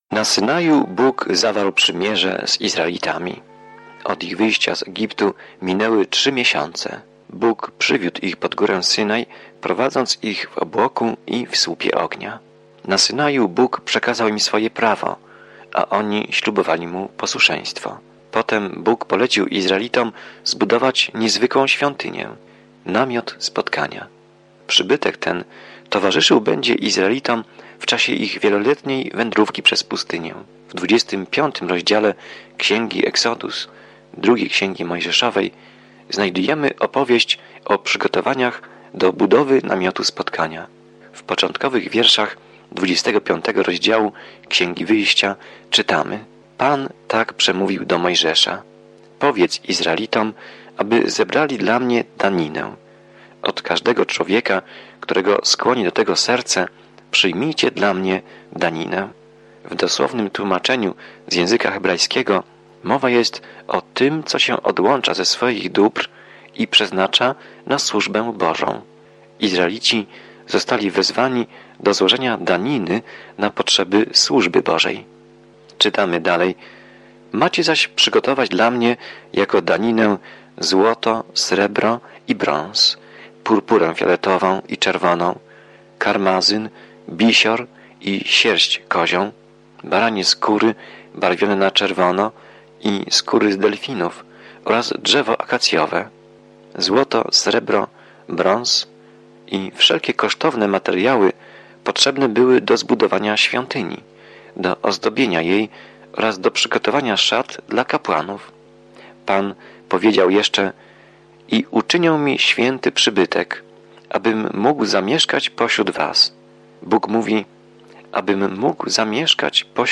Pismo Święte Wyjścia 25 Dzień 16 Rozpocznij ten plan Dzień 18 O tym planie Exodus śledzi ucieczkę Izraela z niewoli w Egipcie i opisuje wszystko, co wydarzyło się po drodze. Codzienna podróż przez Exodus, słuchanie studium audio i czytanie wybranych wersetów słowa Bożego.